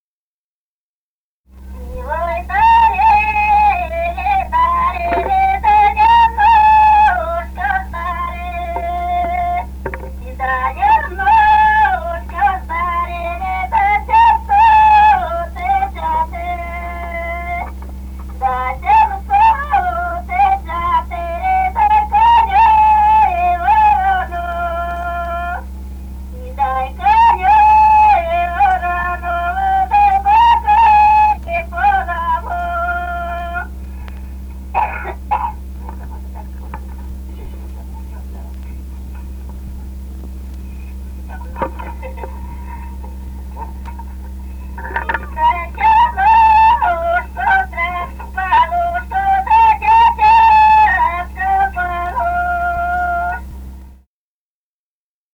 «И ой, дарили, дарили» (свадебная). с. Дзержинское Дзержинского района.